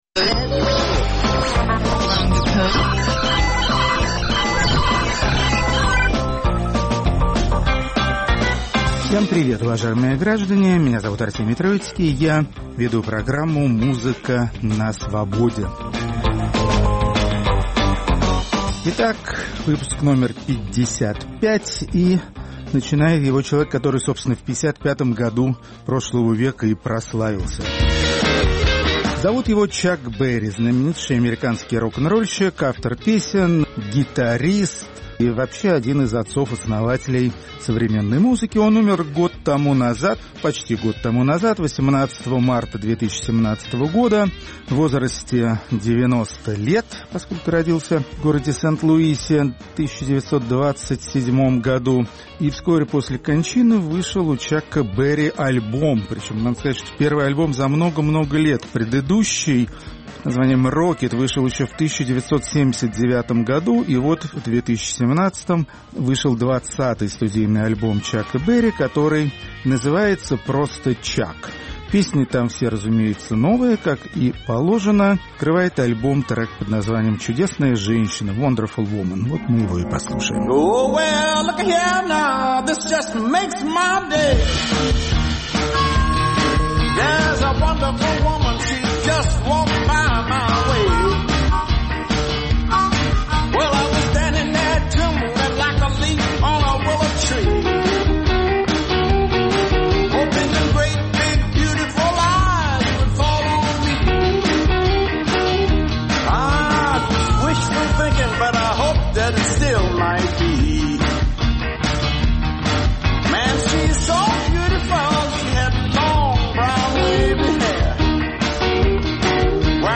Исполнители психоделических рок-композиций.